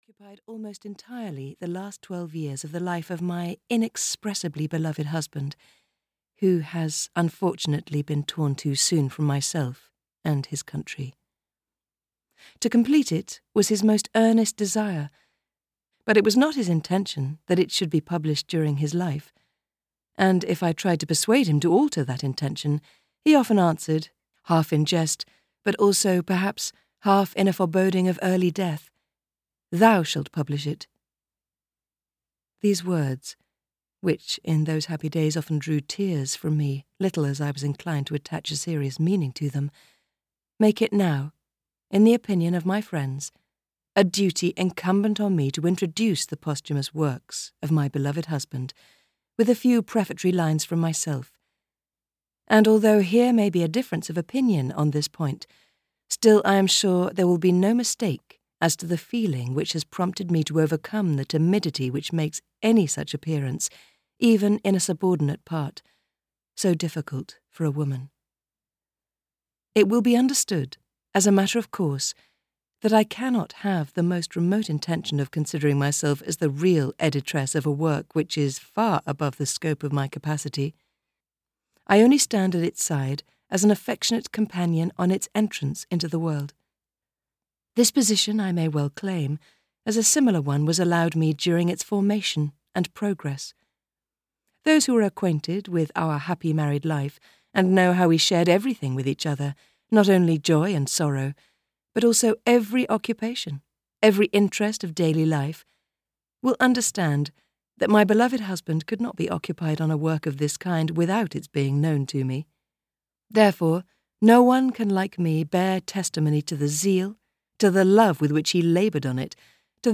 On War (EN) audiokniha
Ukázka z knihy